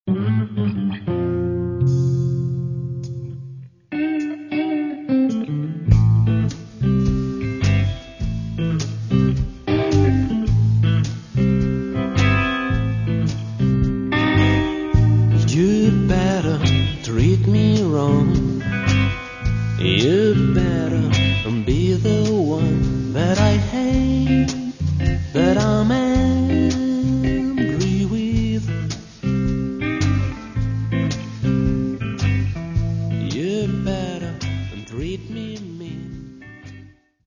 Каталог -> Рок и альтернатива -> Сборники